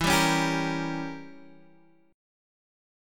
Gdim/E chord
G-Diminished-E-x,7,5,6,8,6.m4a